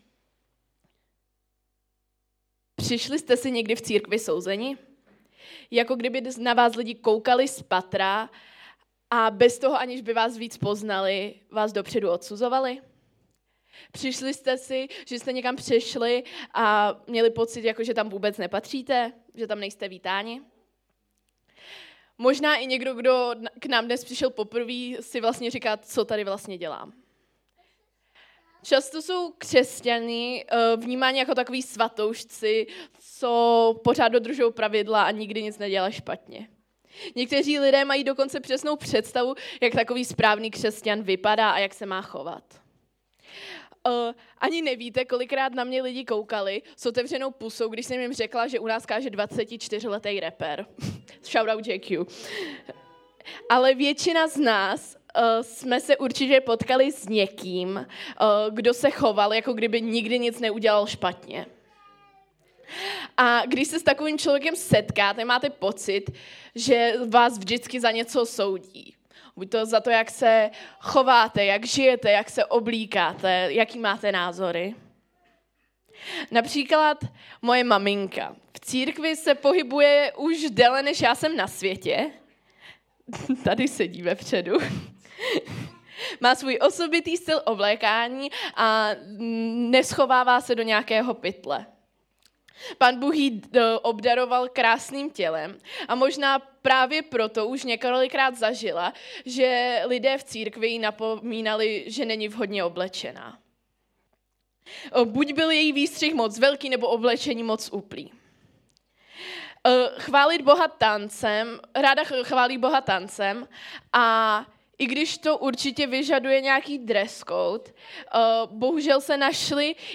Omlouváme se, ale pro technickou závadu kázání není celé.